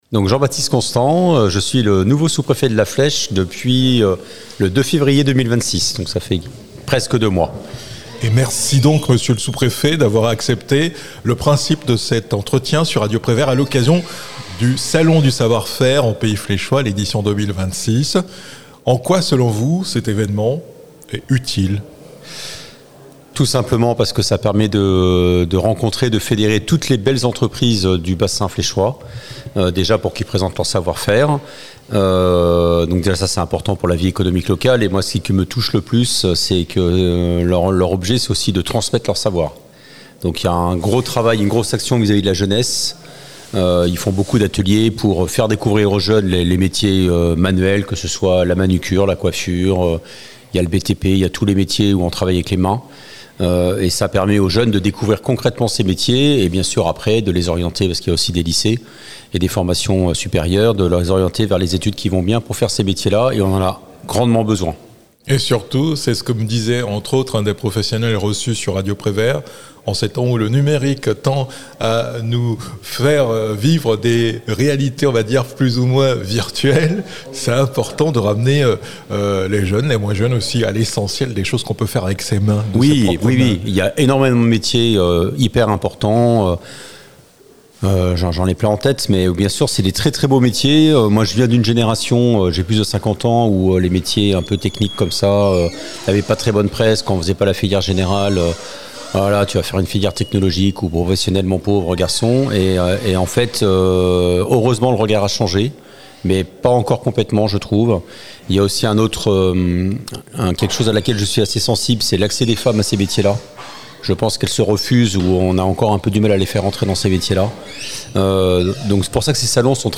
Jean-Baptiste Constant, sous-préfet de l'arrondissement de La Flèche, a participé à l'inauguration du Salon du savoir-faire en Pays Fléchois, le vendredi 27 mars 2028 à La Halle-au-Blé à La Flèche. L'occasion pour lui d'échanger avec les professionnels et d'attirer l'attention sur l'importance d'accueillir les jeunes pour leur faire découvrir leurs métiers et leur transmettre leur savoir-faire.